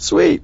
gutterball-3/Gutterball 3/Commentators/Bill/b_sweet.wav at 30d6695f0c05e0159e645caab8c4e19b00ced065